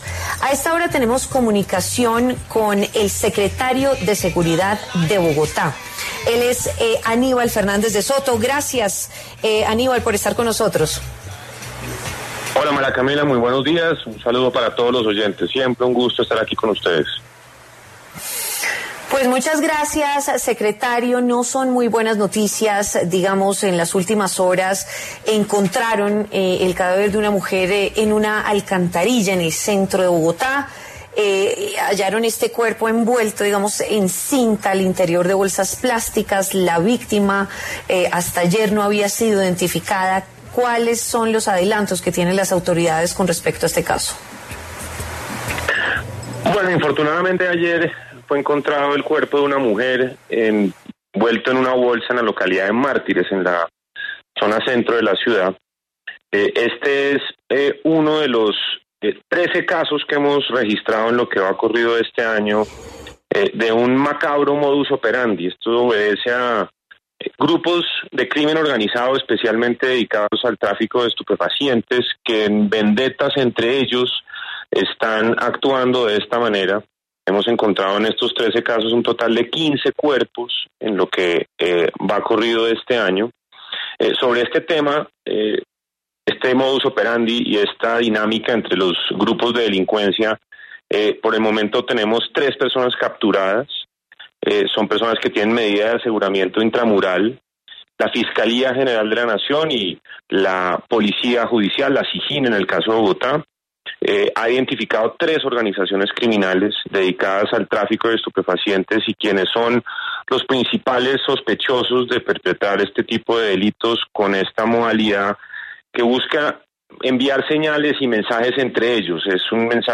Aníbal Fernández de Soto, subsecretario de seguridad de Bogotá, habló en W Fin de Semana sobre el modus operandi de grupos ilegales.